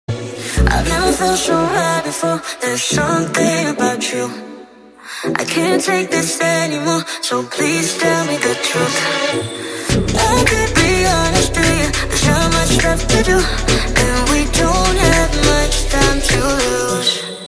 欧美歌曲 流行